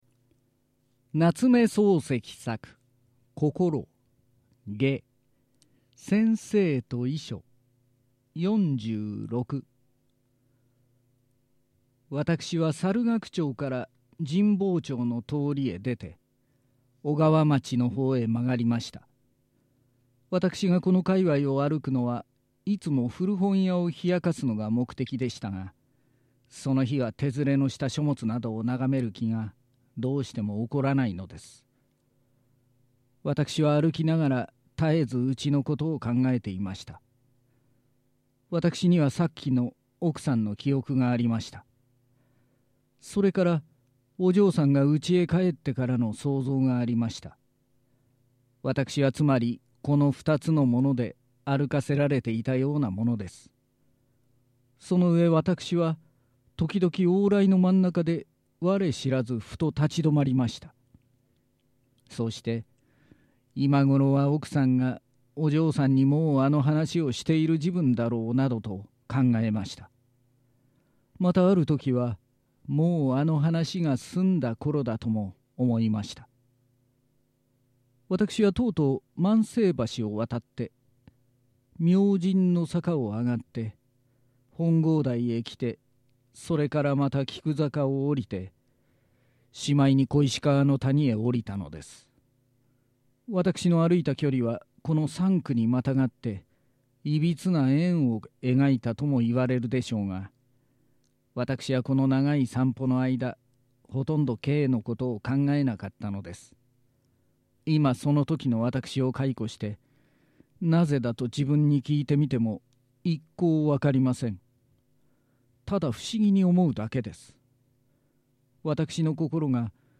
表現よみとは文学作品の原文を生かした音声表現です。